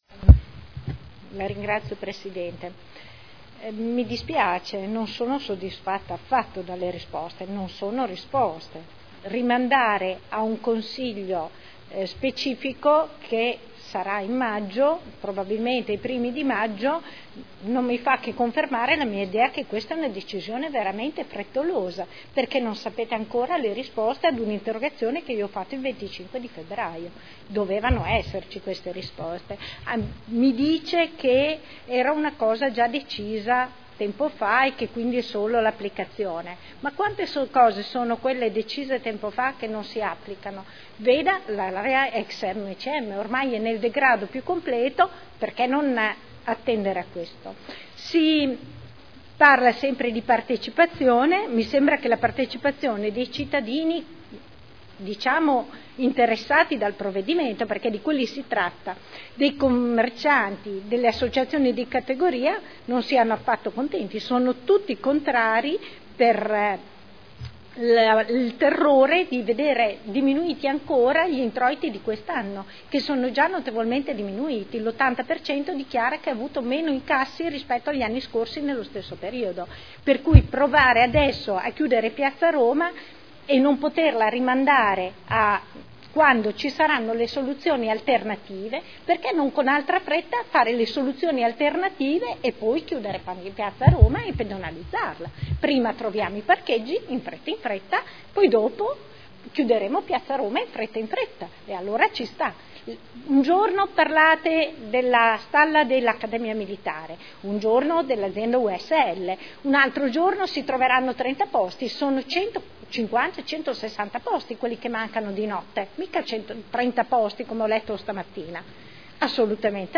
Luigia Santoro — Sito Audio Consiglio Comunale